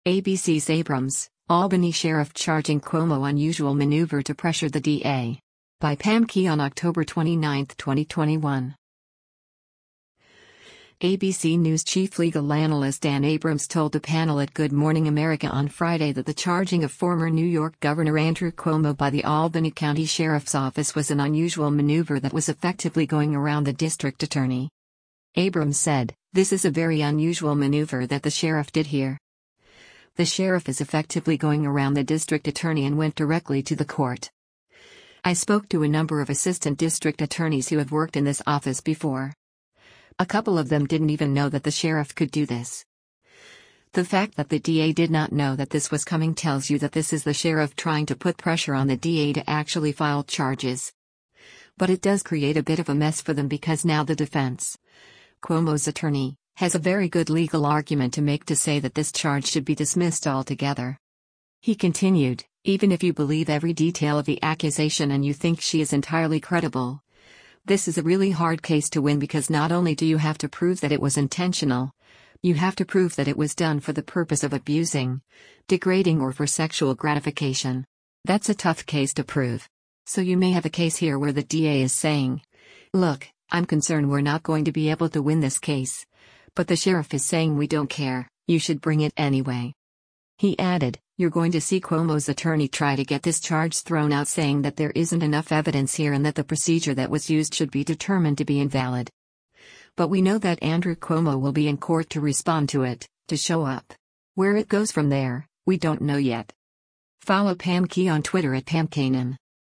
ABC News chief legal analyst Dan Abrams told the panel at “Good Morning America” on Friday that the charging of former New York Governor Andrew Cuomo by the Albany County Sheriff’s Office was an “unusual maneuver” that was “effectively going around the district attorney.”